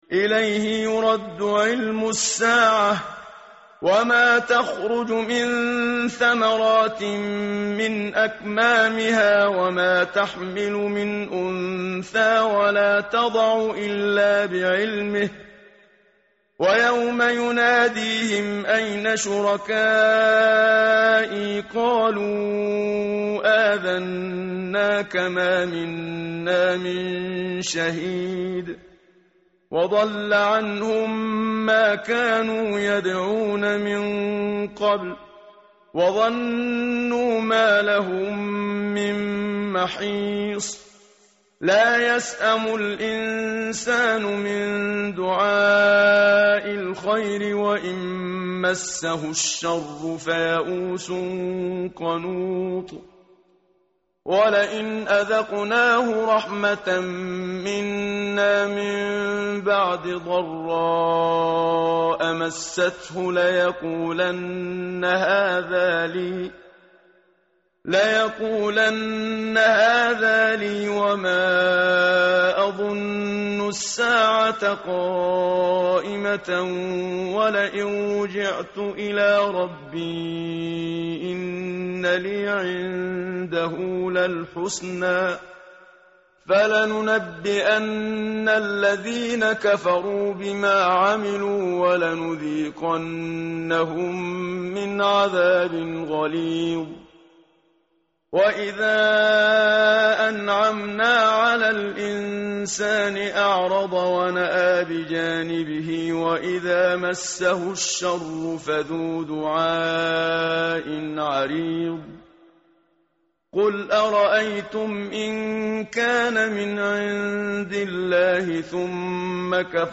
متن قرآن همراه باتلاوت قرآن و ترجمه
tartil_menshavi_page_482.mp3